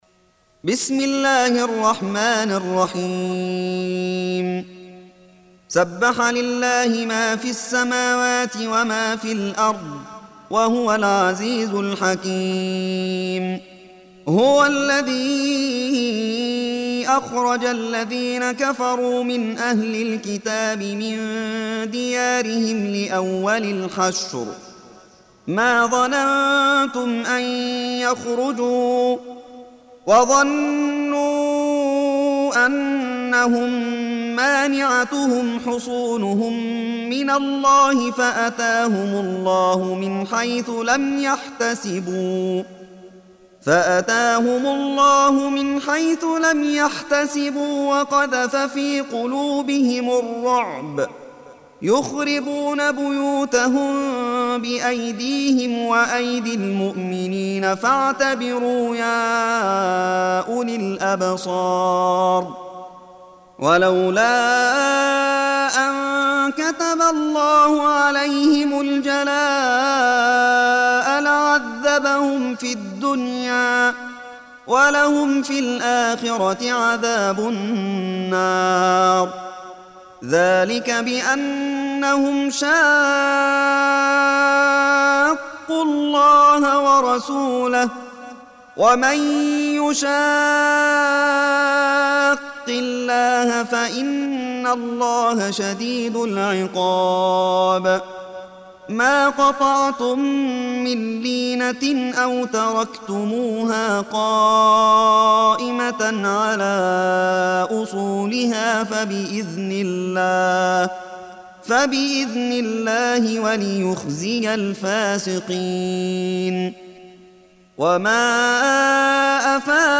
Surah Sequence تتابع السورة Download Surah حمّل السورة Reciting Murattalah Audio for 59. Surah Al-Hashr سورة الحشر N.B *Surah Includes Al-Basmalah Reciters Sequents تتابع التلاوات Reciters Repeats تكرار التلاوات